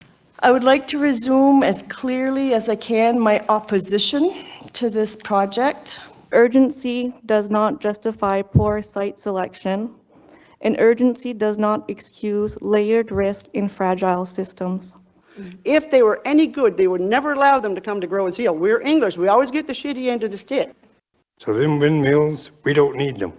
La deuxième partie de l’audience publique du Bureau d’audiences publiques sur l’environnement (BAPE) pour le projet du parc éolien de Grosse-Île a débuté mardi soir.
Une quarantaine de personnes étaient rassemblées dans le gymnase de Grosse-Île à cette occasion.
La moitié d’entre eux étaient des personnes de la communauté anglophone de l’Est qui se sont fermement opposés au projet: